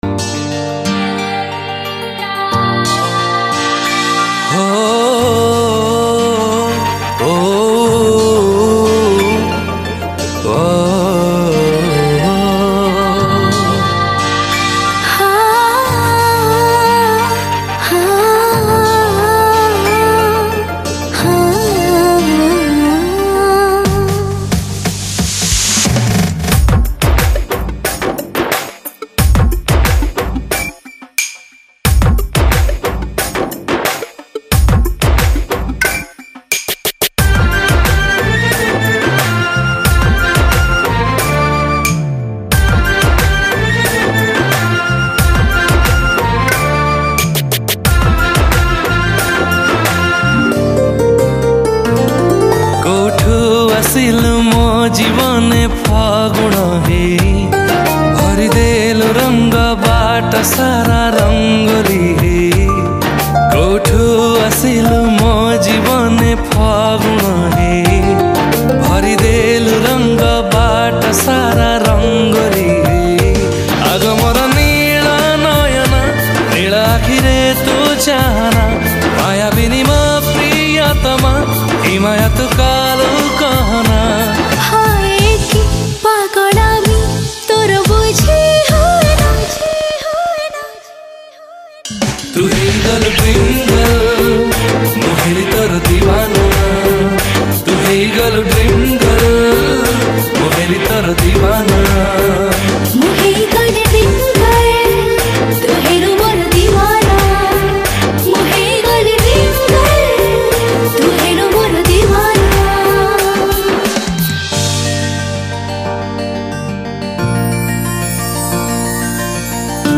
BEST ROMANTIC ODIA